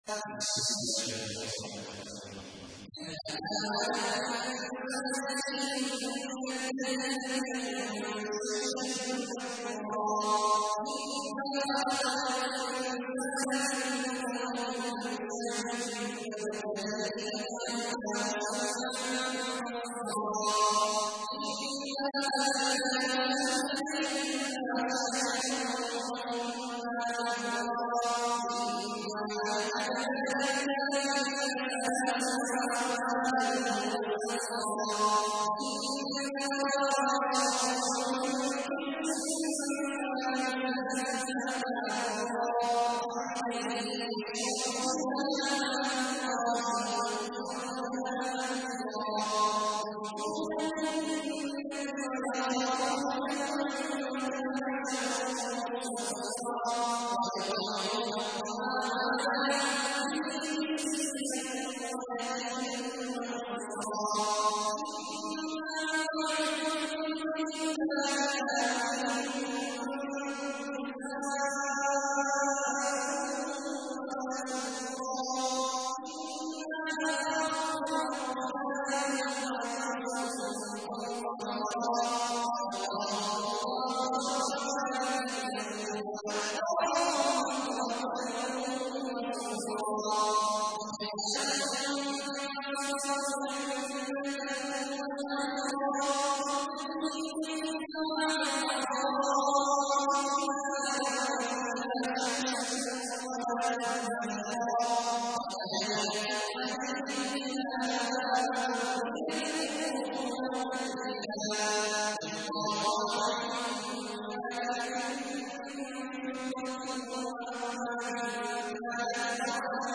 تحميل : 76. سورة الإنسان / القارئ عبد الله عواد الجهني / القرآن الكريم / موقع يا حسين